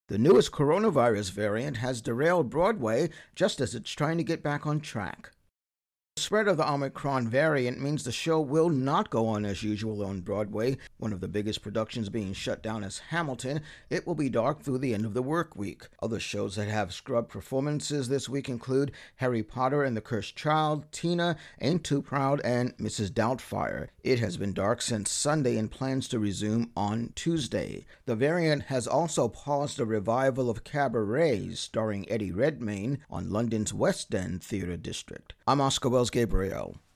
Intro+voicer on virus variant causing a revival of theater closings.